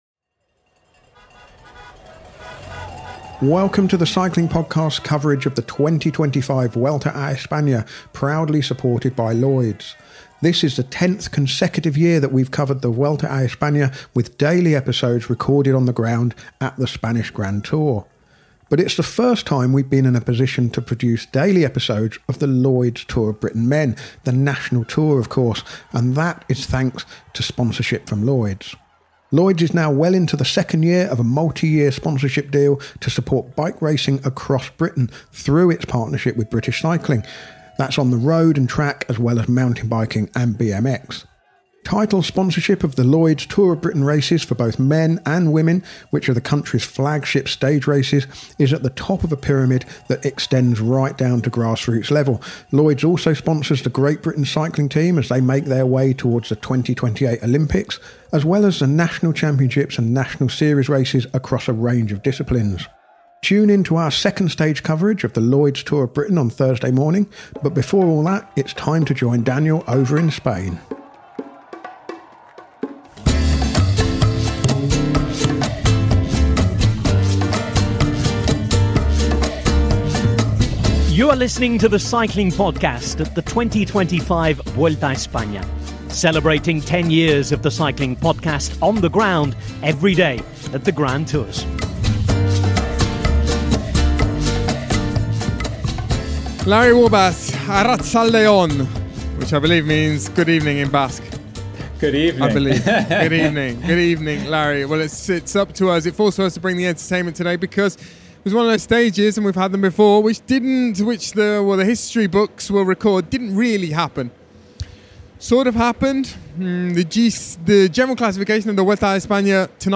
Join us for daily coverage of the Vuelta a España recorded on the road as the race makes its way from Turin to Madrid. Our daily coverage features race analysis, interviews and daily postcards from Spain.